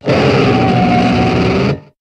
Cri de Gigalithe dans Pokémon HOME.